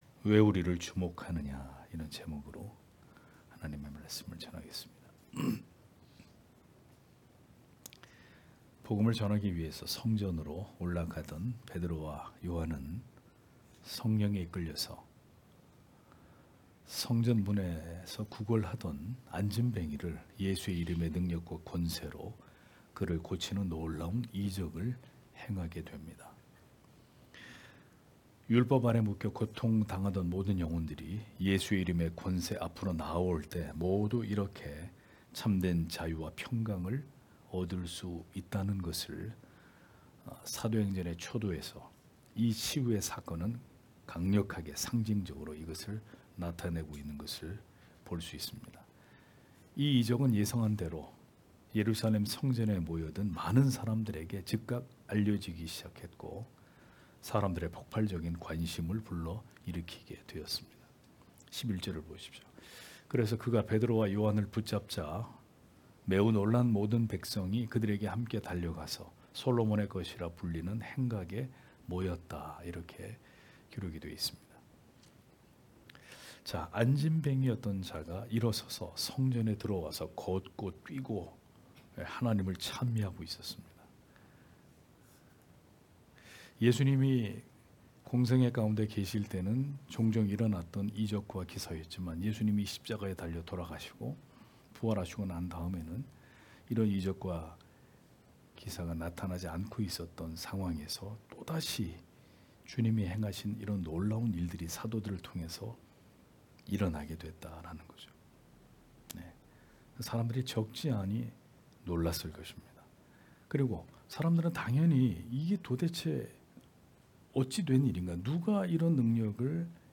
금요기도회 - [사도행전 강해 21] 왜 우리를 주목하느냐 (행 3장 11-15절)